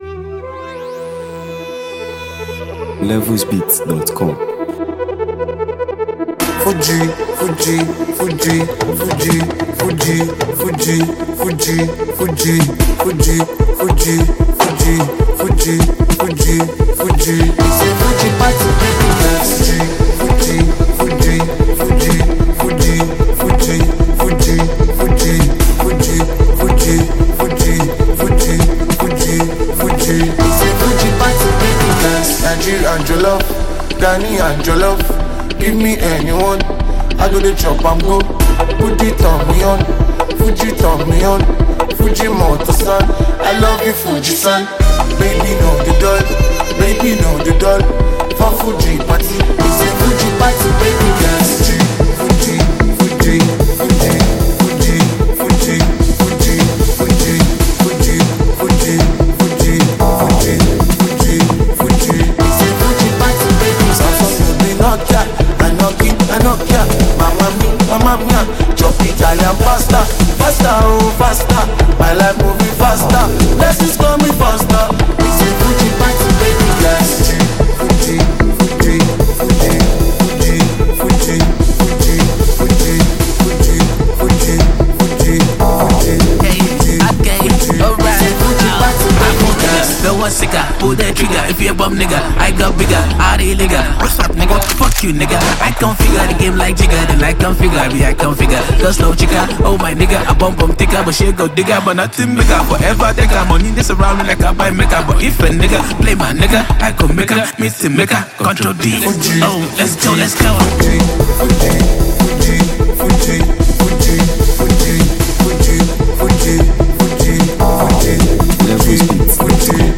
Nigeria Music 2025 2:03
A Vibrant Blend of Street Vibes and Fuji Energy
crafted with top-tier production and unforgettable hooks.